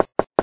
防空警报声
描述：防空警报声